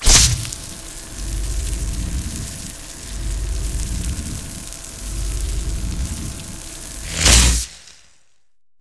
ImpIdle.ogg